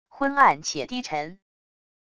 昏暗且低沉wav音频